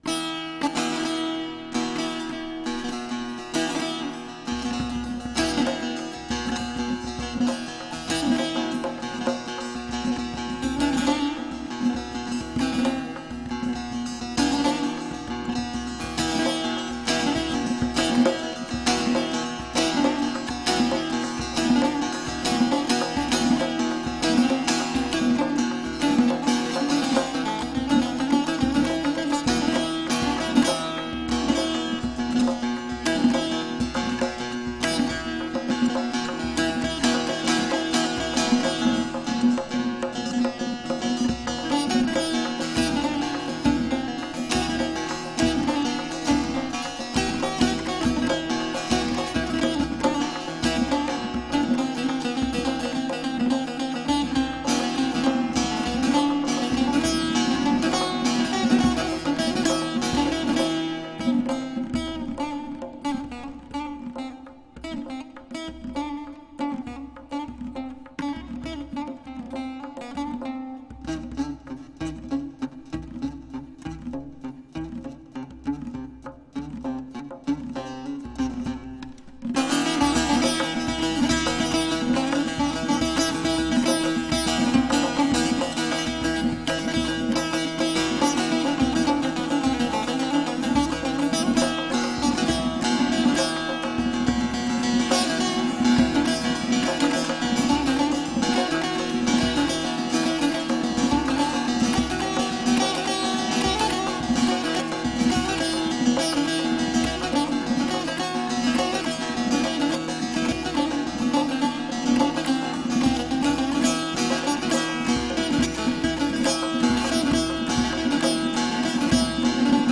بداهه نوازی در آواز ابوعطا سه تار
تمبک